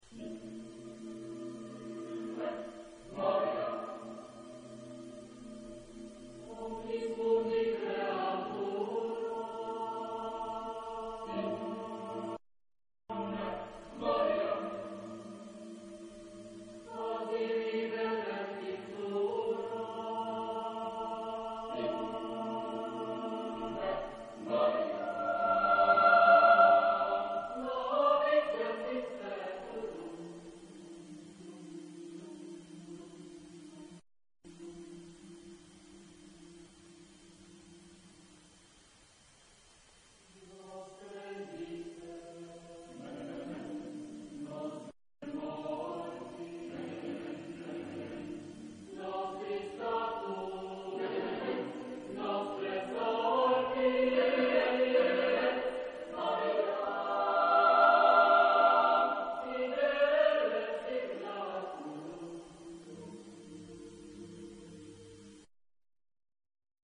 Pièce vocale ; Choral ; Sacré
SATB + SATB (8 voix Double Chœur )
Tonalité : libre